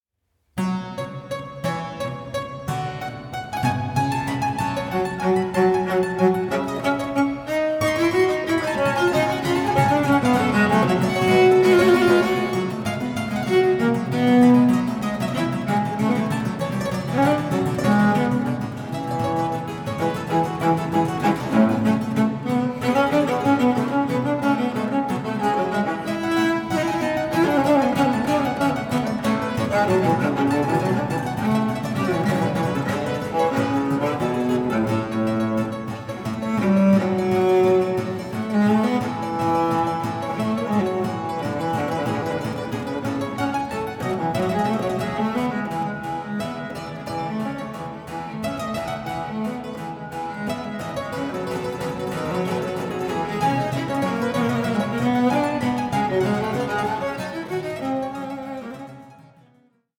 Allegro 3:53